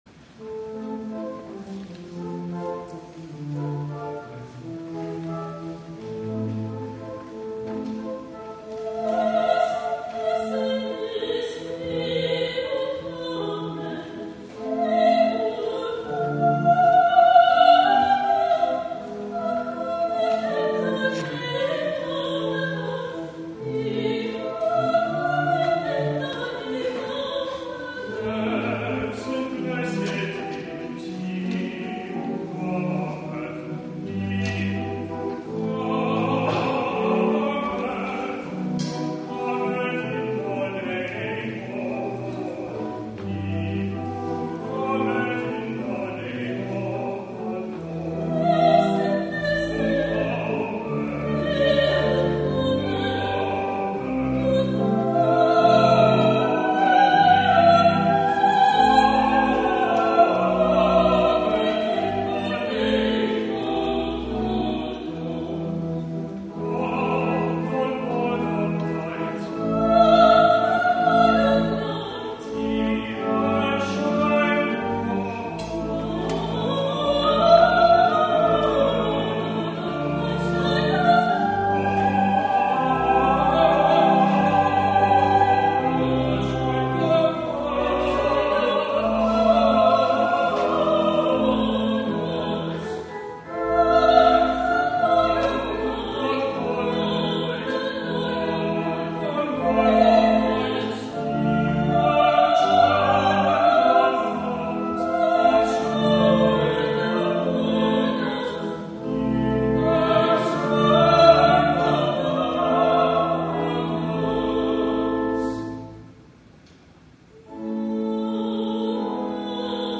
THE OFFERTORY
soprano
baritone